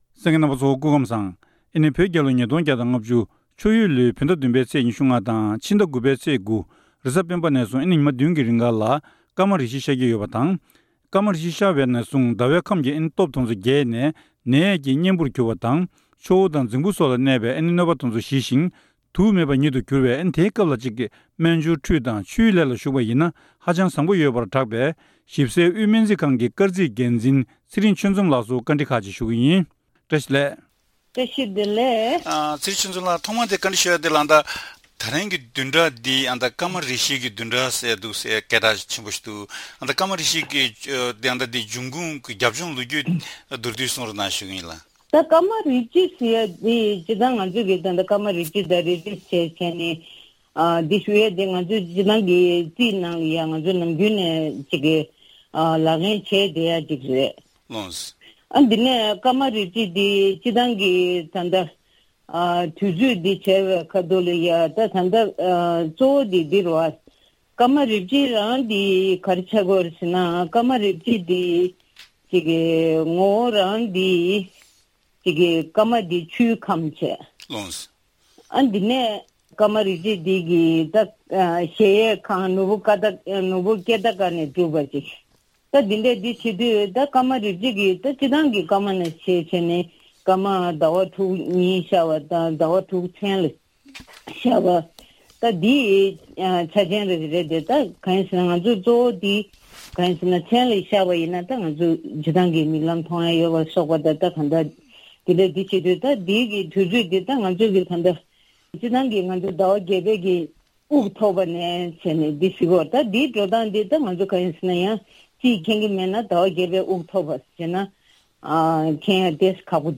དེ་རིང་གི་བཅར་འདྲིའི་ལེ་ཚན་ནང་།